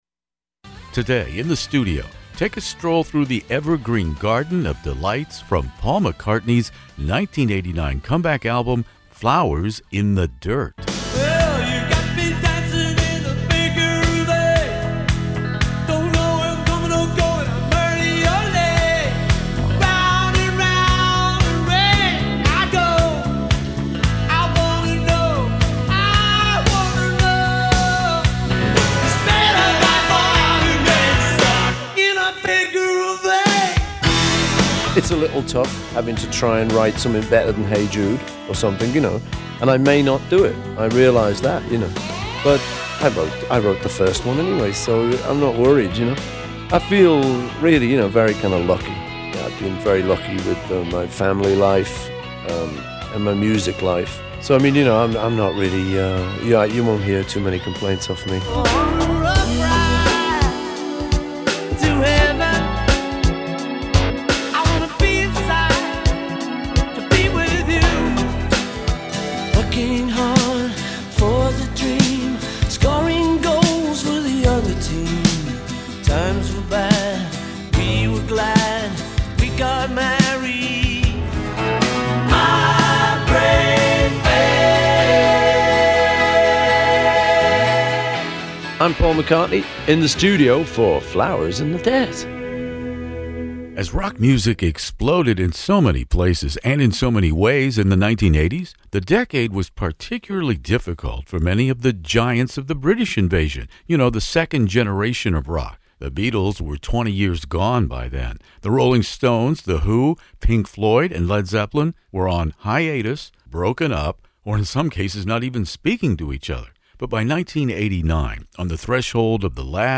We are thrilled and honored to welcome back Sir Paul McCartney to guest host In the Studio!